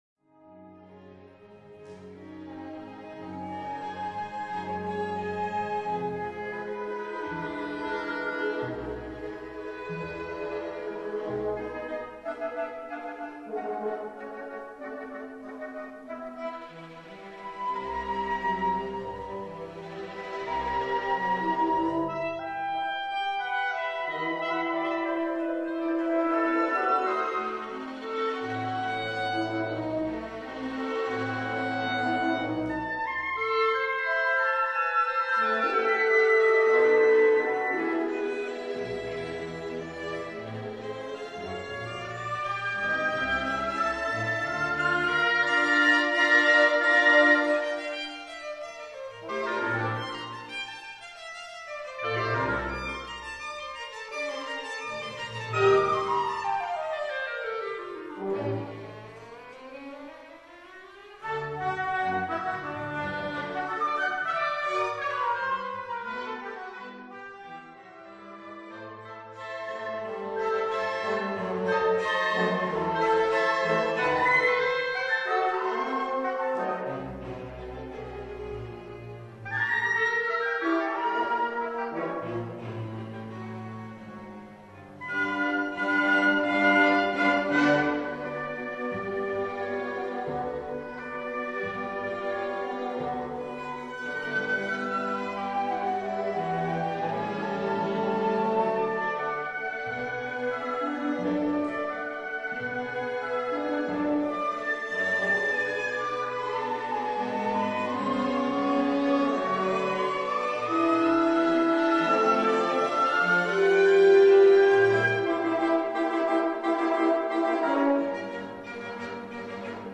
Fra koncert i maj 2003